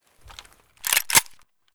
mp133_close_empty.ogg